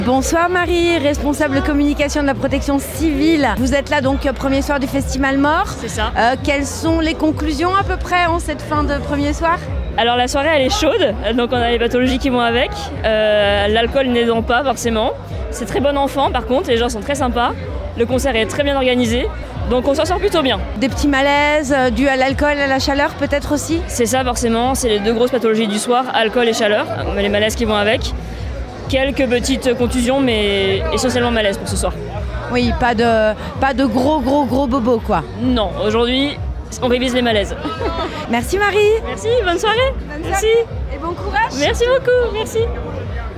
Interviews Festi'malemort email Rate it 1 2 3 4 5